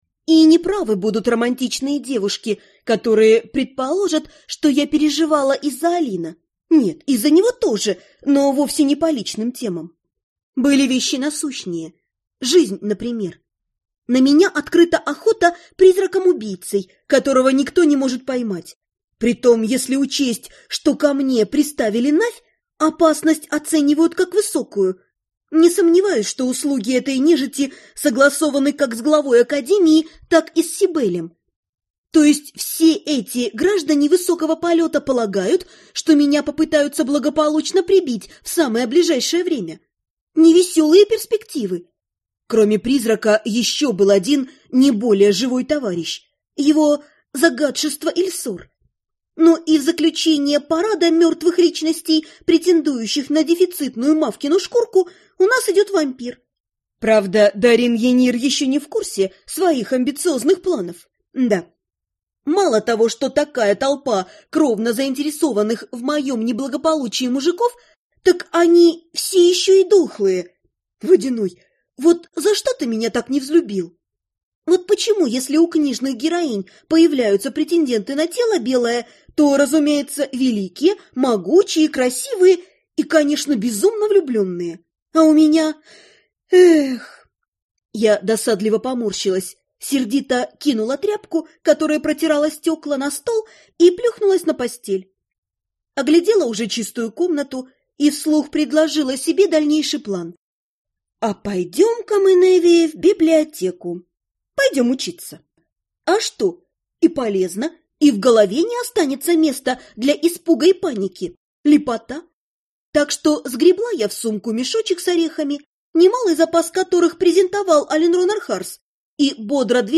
Аудиокнига Тайна василиска - купить, скачать и слушать онлайн | КнигоПоиск